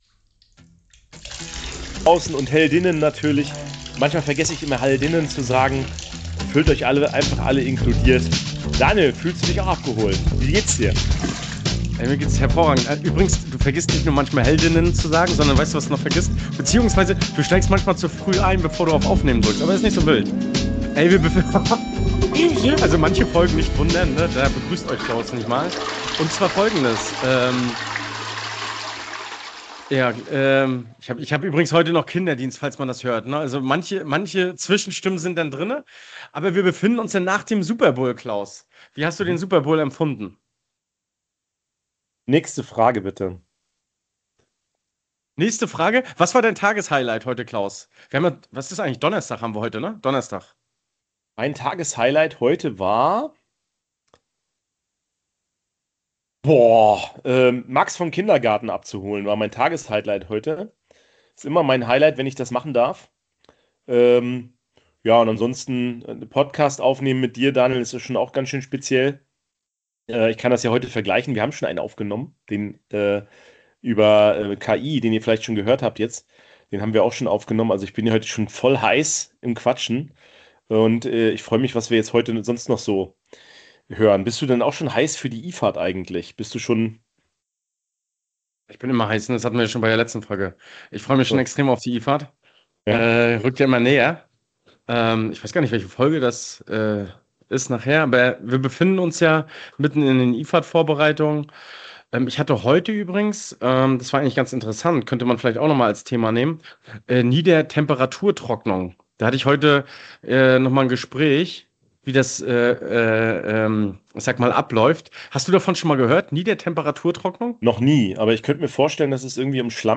Warum entsteht Schwefelwasserstoff im Faulturm – und wann wird er zum Problem? Wir sprechen mit zwei Experten über Chemie, Betrieb und Sicherheit rund um H₂S im Faulgas. Außerdem geht es um praktische Lösungen von Eisenfällung bis Mikrobelüftung.